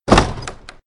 Исправить звук дверей: ... - преобразовать звук в стерео; - уменьшить уровень звука